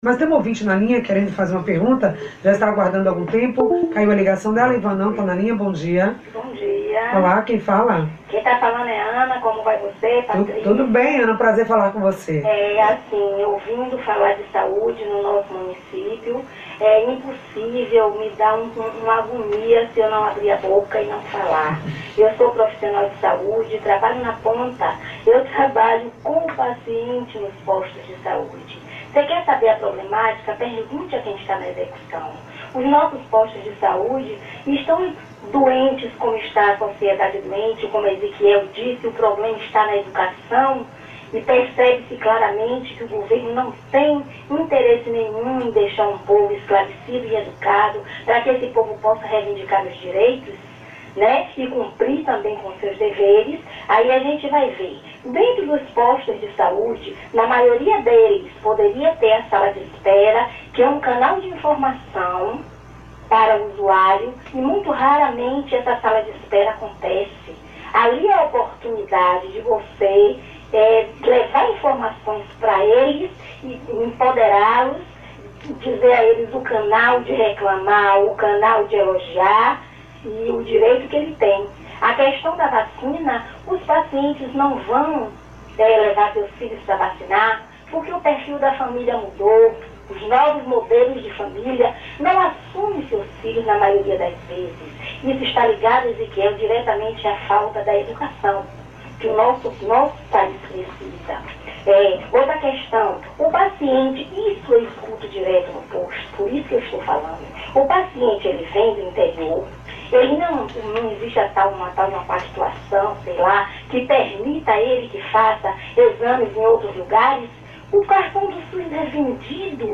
Ouça a fala comovente  de uma ouvinte do programa:
A-FALA-DA-OUVINTE.mp3